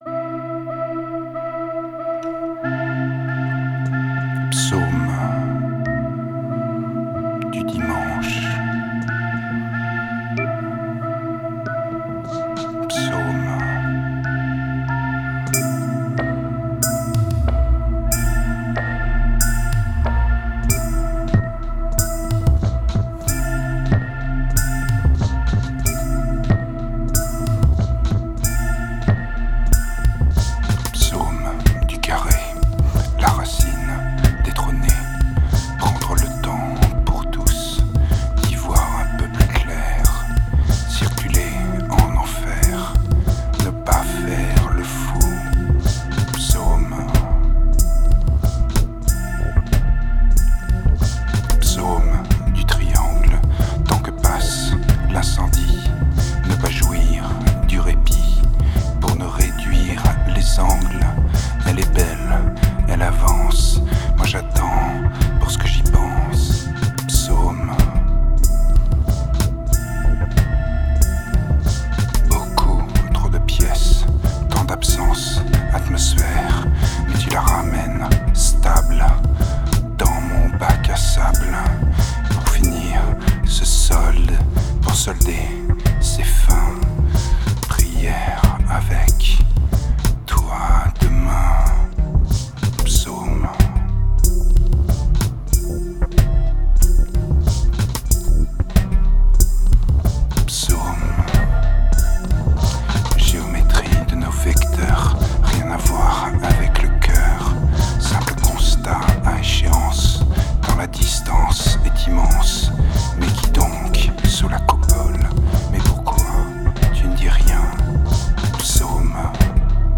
synthetic pop